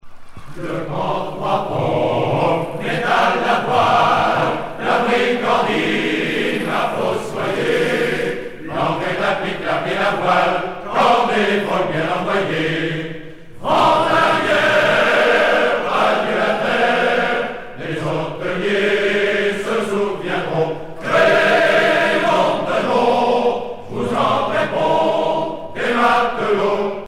Genre strophique
Ensemble choral